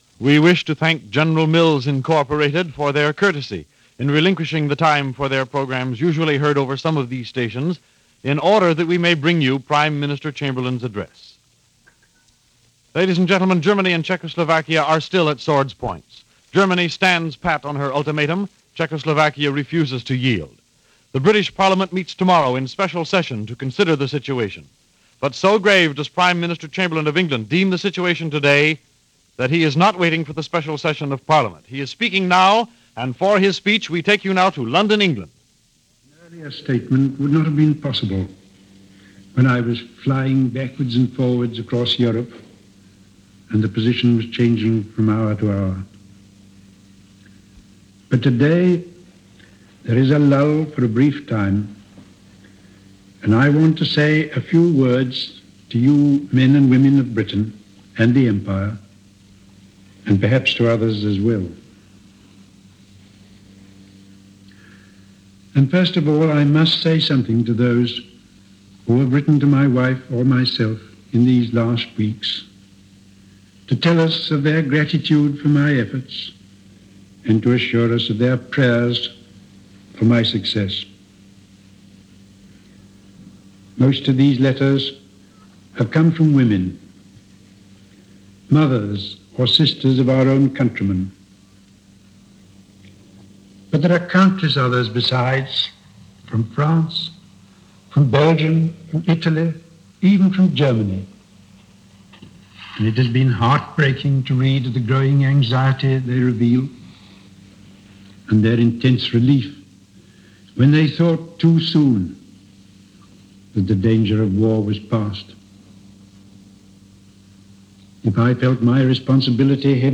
British Prime Minister Chamberlain, exhausted from the rigors of Shuttle Diplomacy, was about to present his case to Parliament, but decided to address the nation (and the world via shortwave) on the situation as it stood before Parliament convened.